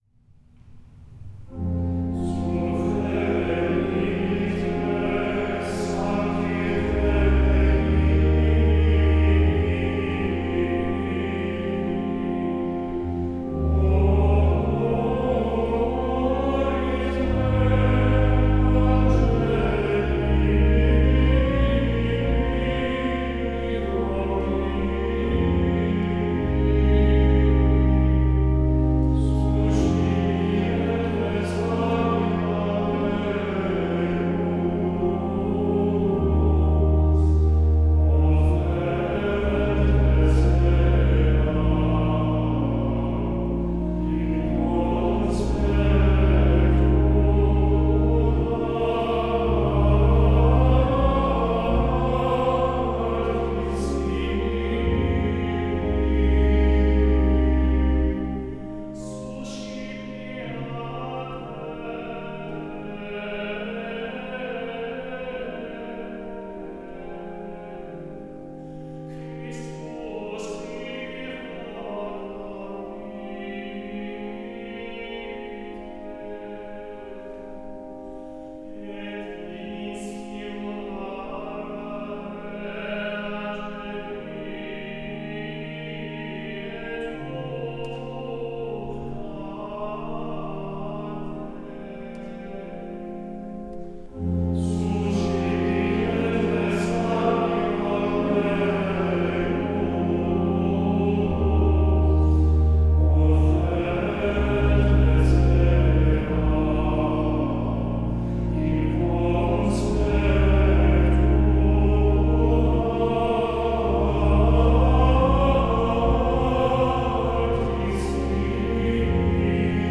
This session features something different from the recordings we’ve made so far: instead of devotional hymns in English, we’re presenting the sung texts of the Mass itself, in Latin.
a group of singers
at the glorious parish church in Clinton, Massachusetts
chants
with organ accompaniment